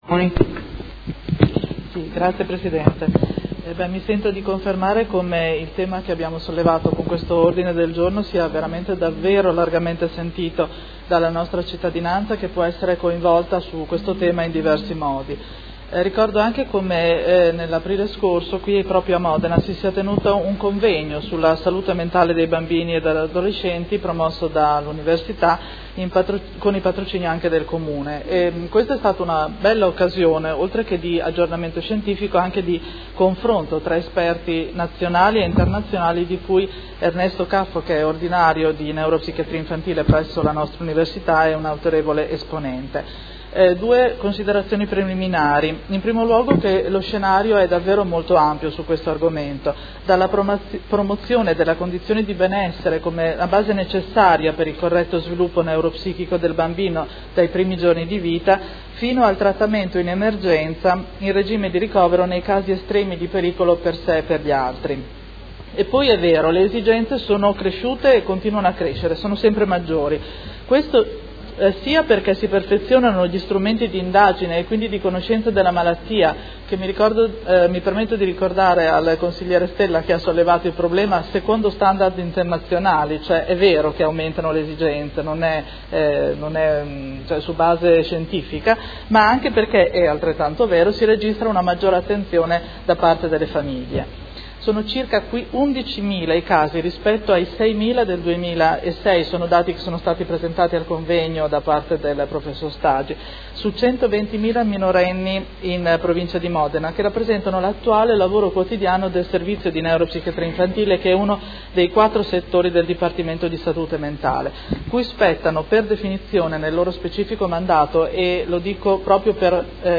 Seduta del 3/05/2018. Dibattito su Ordine del Giorno presentato dai Consiglieri Baracchi, Pacchioni, Fasano, Arletti, Forghieri, Lenzini, Di Padova, Venturelli, Morini, Poggi, Liotti e De Lillo (PD)avente per oggetto: Verifica e approfondimento progetti d’inclusione alunni e alunne con disabilità